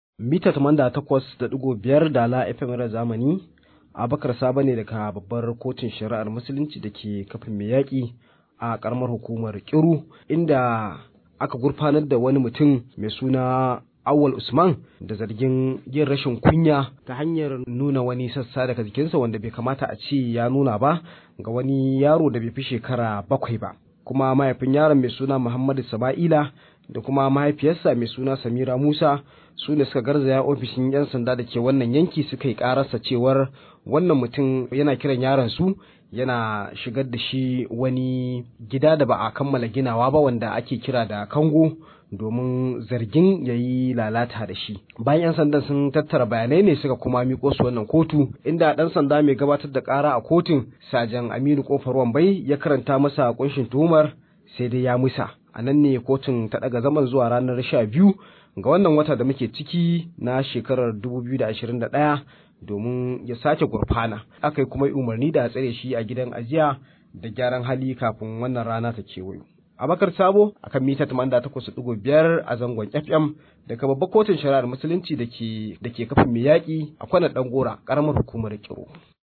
Rahoto: Wani mutum ya gurfana a kotu kan zargin yunkurin lalata yaro